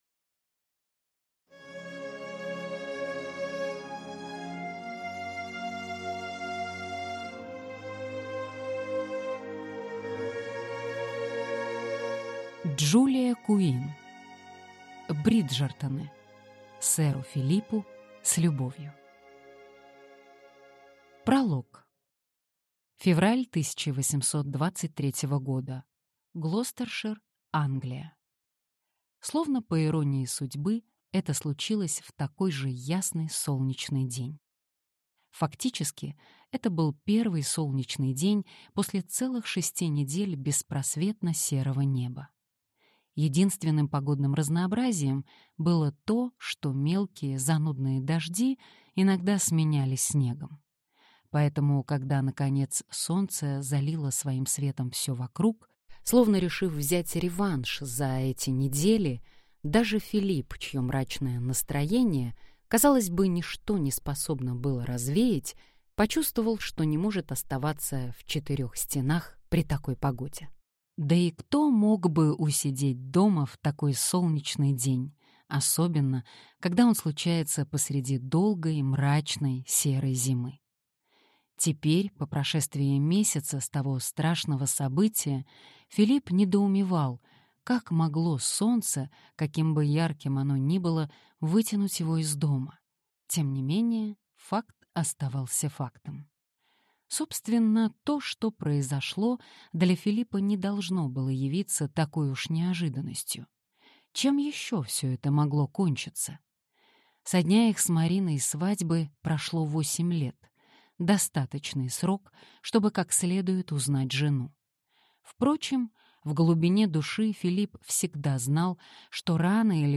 Аудиокнига Сэру Филиппу, с любовью | Библиотека аудиокниг